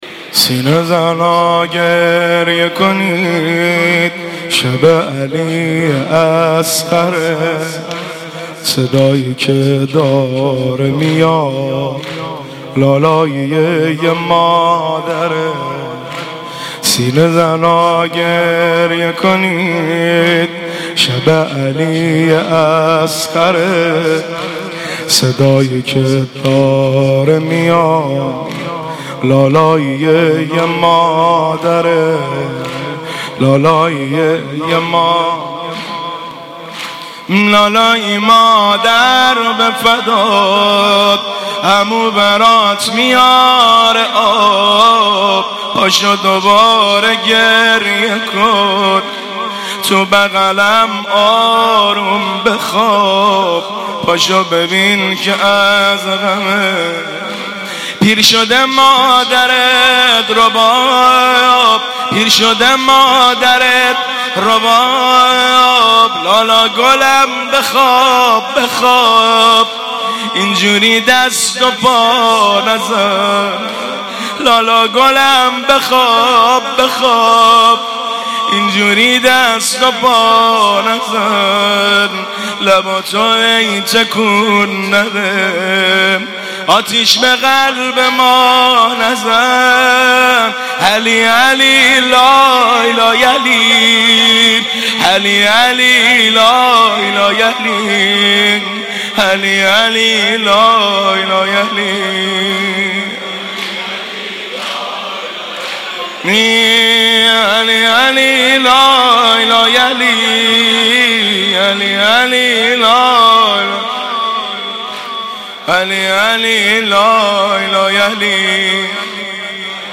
مداحی سینه زنا گریه کنید(زمینه)
شب هفتم محرم 1396
هیئت غریب مدینه امیرکلا